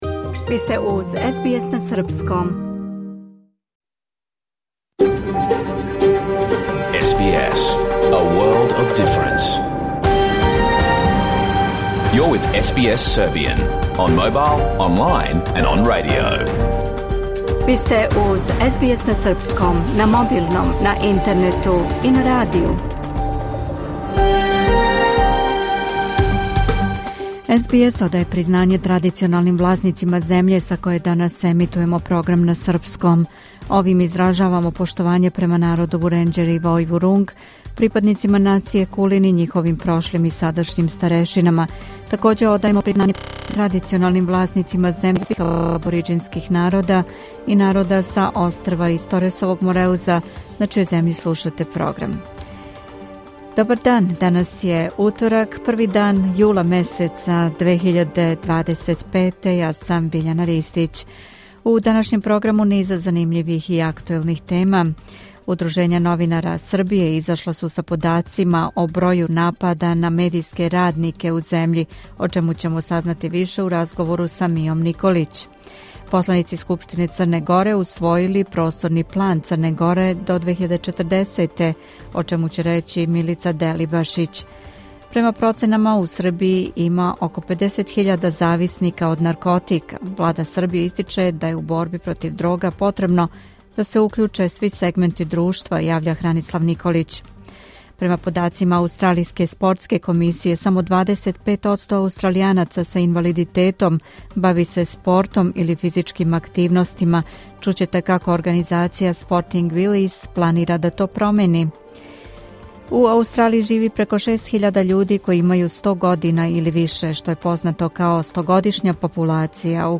Програм емитован уживо 1. јула 2025. године